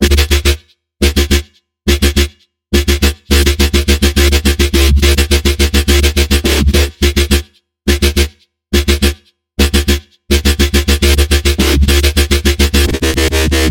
Drum loops " dub disco 2
描述：重型迪斯科配音环与轻摇摆。
标签： 迪斯科 低音 配音
声道立体声